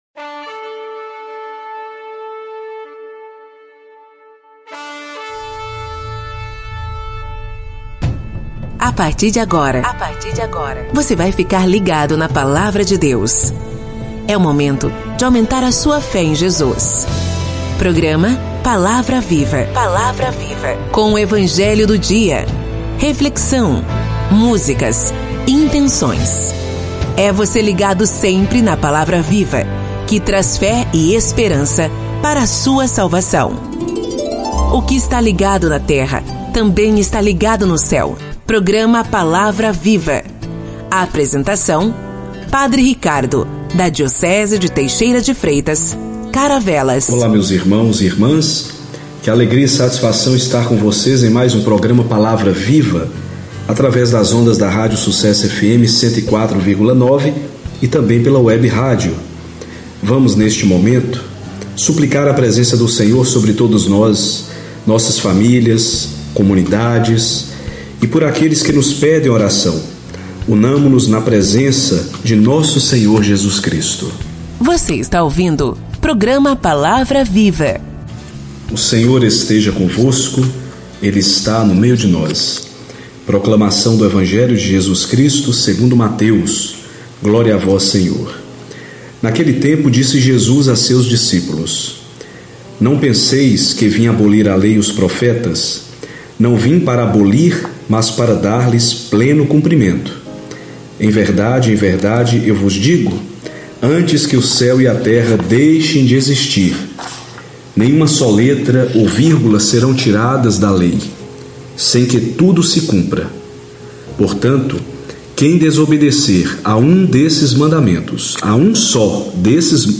Programa: Palavra Viva Episódio: 20º – 16/02/2020 Produção: Rádio Sucesso Fm (104,9) Realização: PASCOM © 2019 – 2020